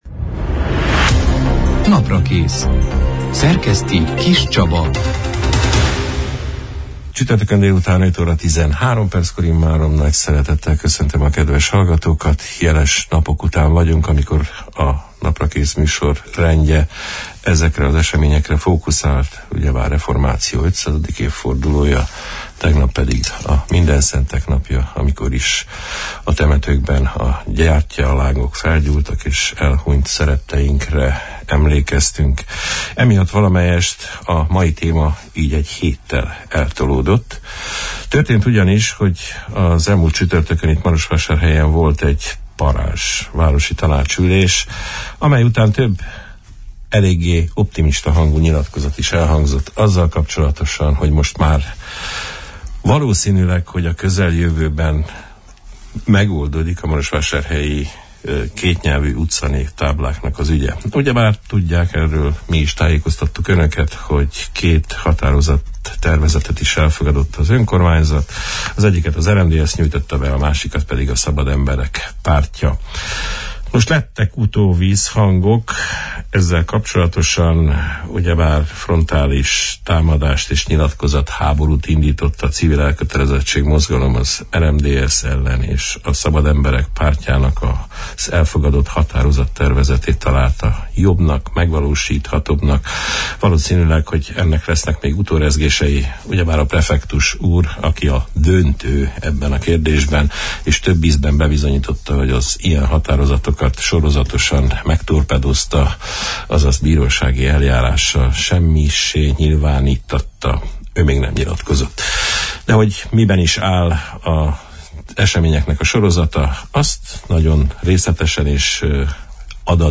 A marosvásárhelyi városi tanács legutóbbi űlésének napirendjéről, a kétnyelvű utcanévtáblákkal kapcsolatos határozatokról, a sportfinanszírozások kérdéséről, a város napjának kinyilvánításáról, a zöldövezetek kibővítéséről, a Bethlen Gábor szobor sorsáról, mindezen javaslatok végrehajtási esélyeiről beszélgettünk a november 2 – án, csütörtökön elhangzott Naprakész műsorban Hermann Mark Christiannal, a Szabad Emberek Pártjának ( POL ) városi tanácsosával.